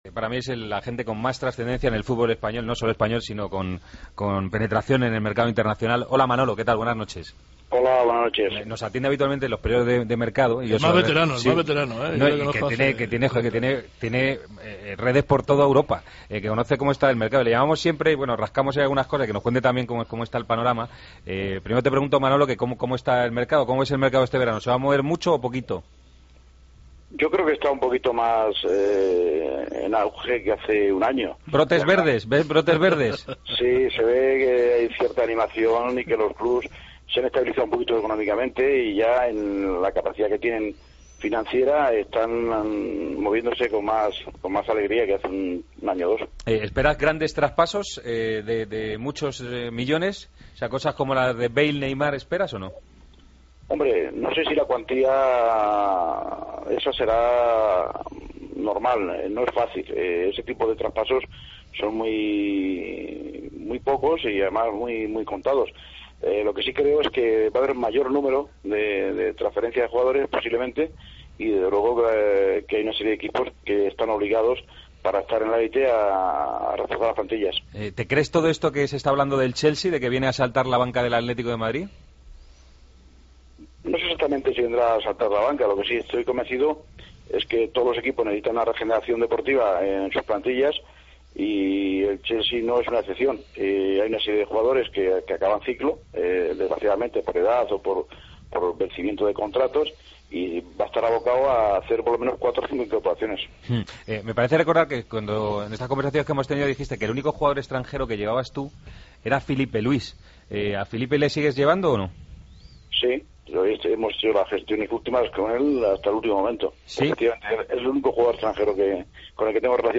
AUDIO: Hablamos con uno de los representantes más importantes del fútbol español, que lleva a jugadores como Gabi, Filipe Luis o Diego...